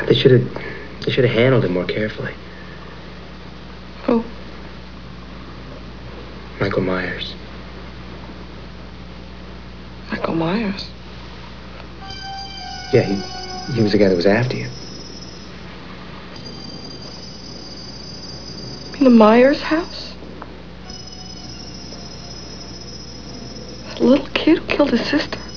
Jimmy telling Laurie about Michael.